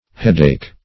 Headache \Head"ache`\ (h[e^]d"[=a]k`), n.